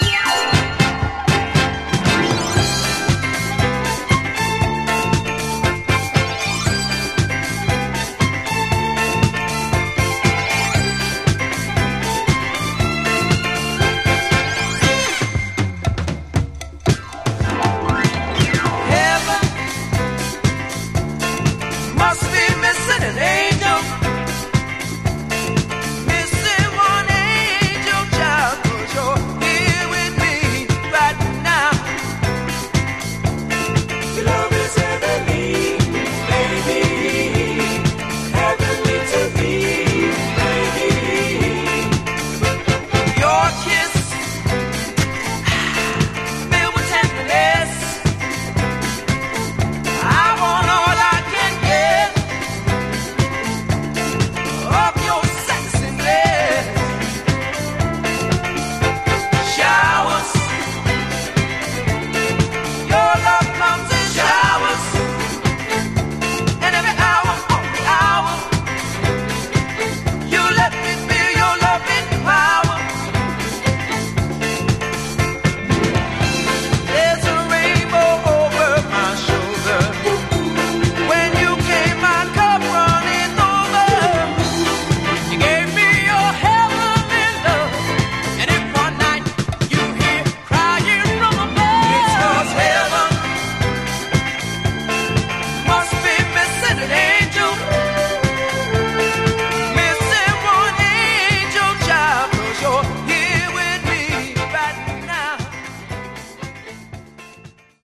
It has Mint labels and pristine sound.